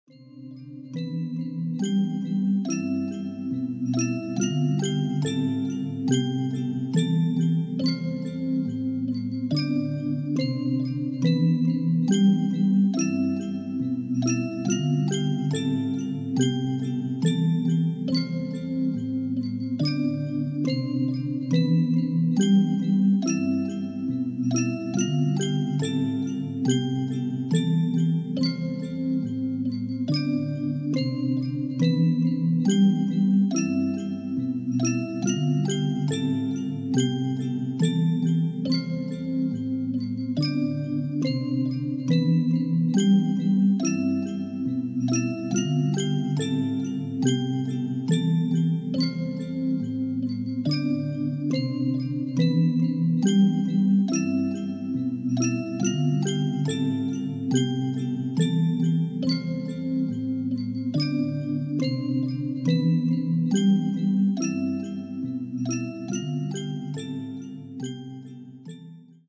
Type BGM
Speed 50%